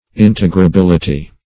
Integrability \In`te*gra*bil"i*ty\, n.